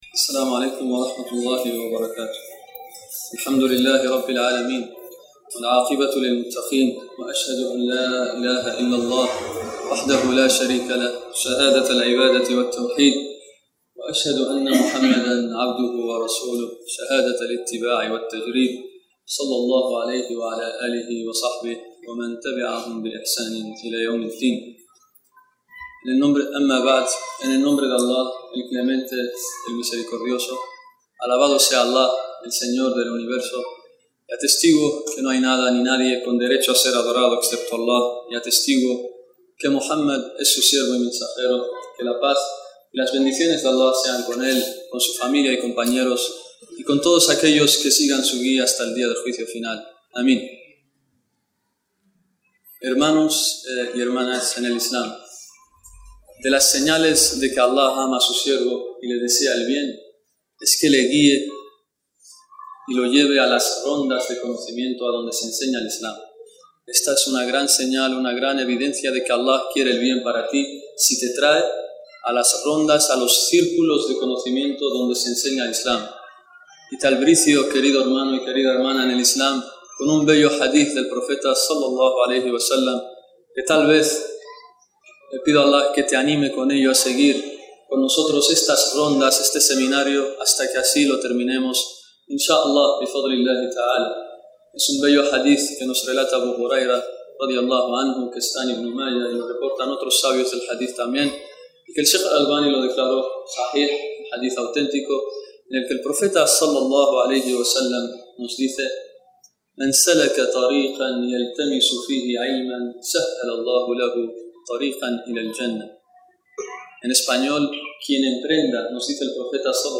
Seminario de creencia islámica (áquidah) en la que se explicó el libro: Tres Principios y sus fun